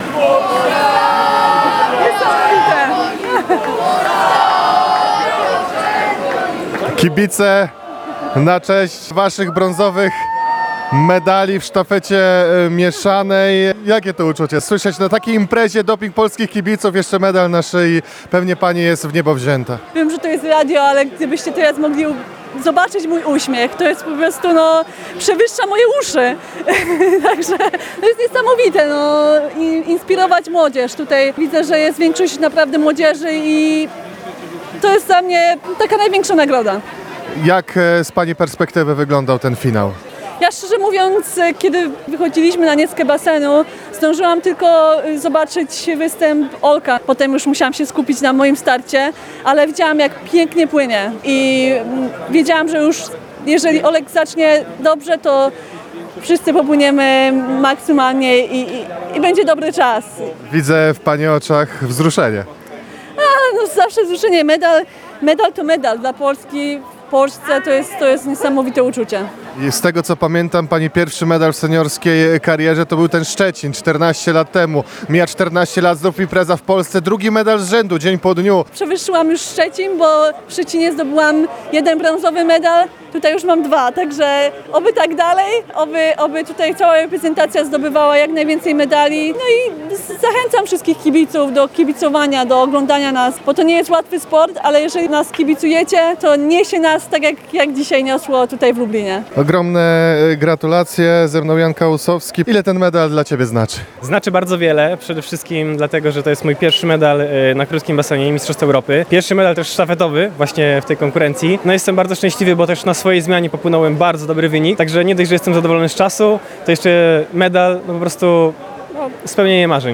Rozmowie towarzyszyli świętujący kibice.